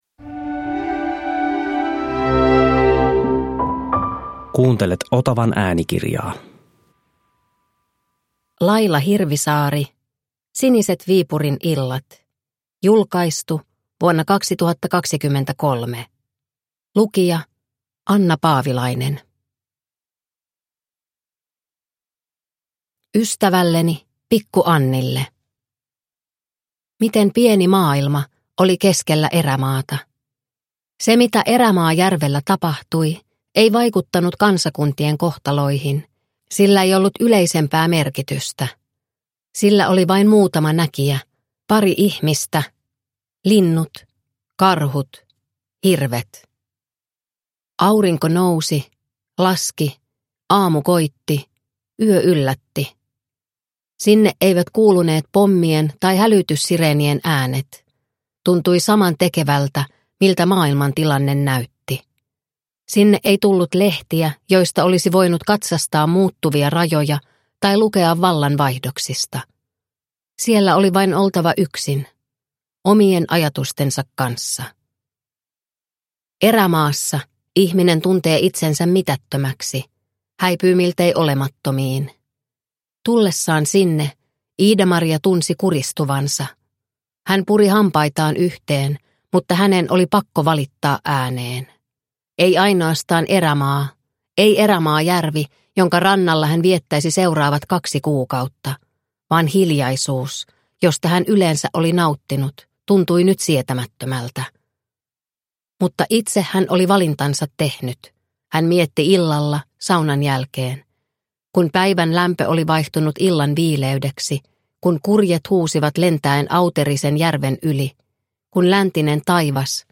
Siniset Viipurin illat (ljudbok) av Laila Hirvisaari